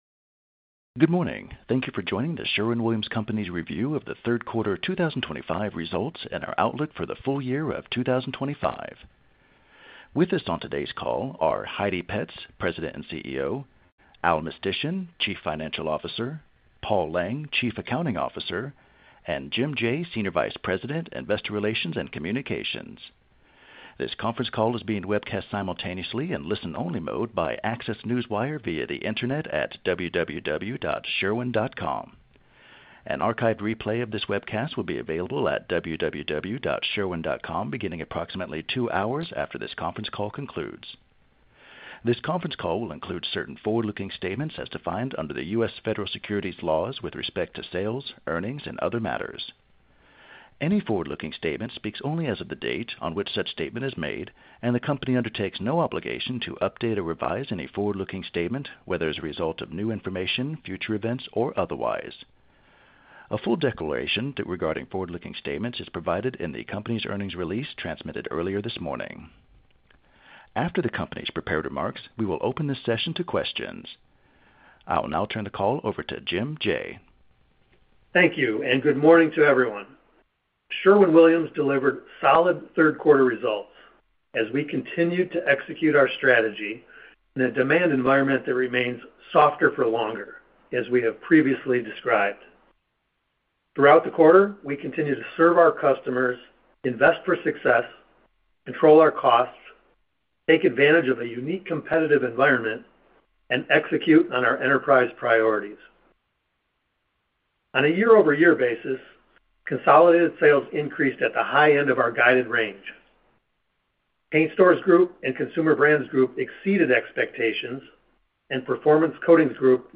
Sherwin-Williams - Sherwin-Williams Third Quarter 2025 Financial Results Conference Call